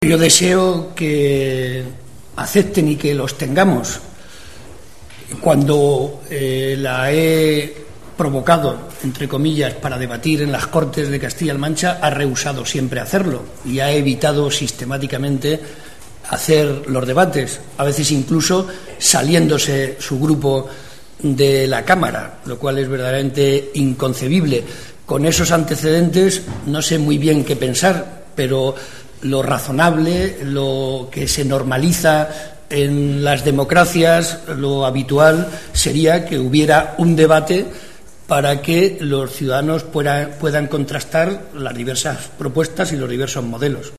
Cortes de audio de la rueda de prensa
Audio-Barreda-debate.mp3